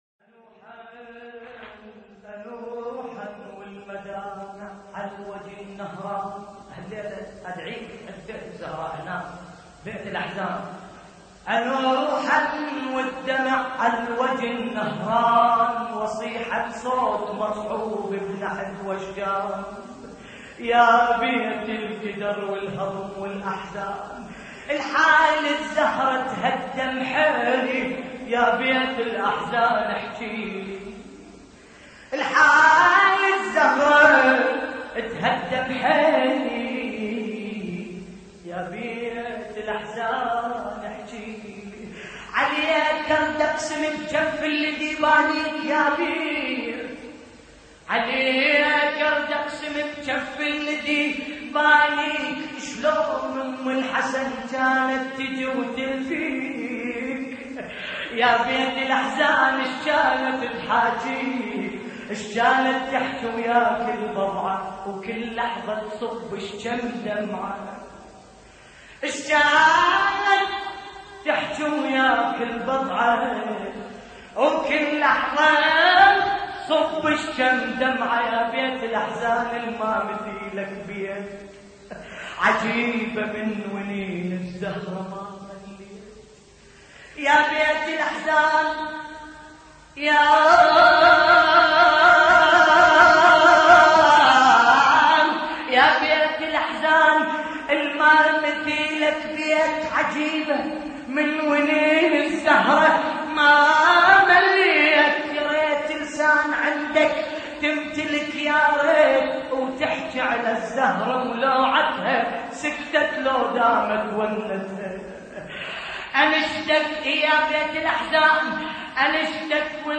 نعي لحفظ الملف في مجلد خاص اضغط بالزر الأيمن هنا ثم اختر